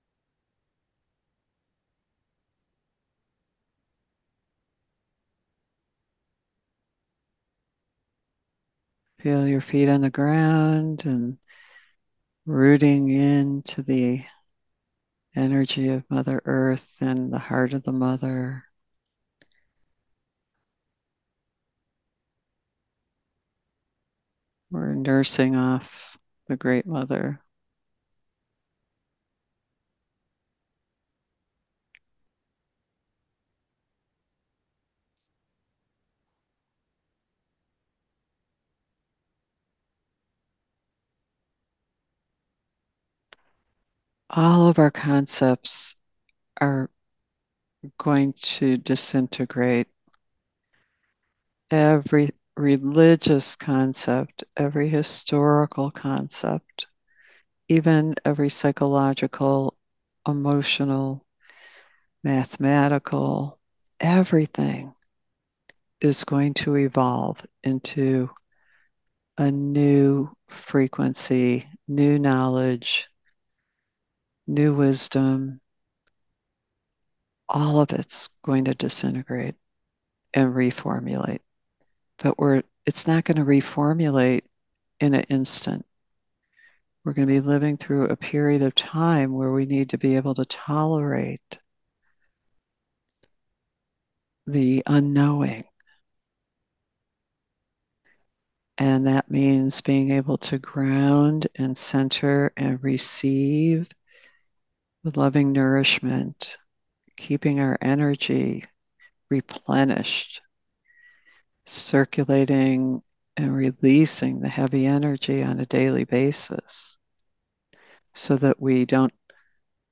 Genre: Guided Meditations.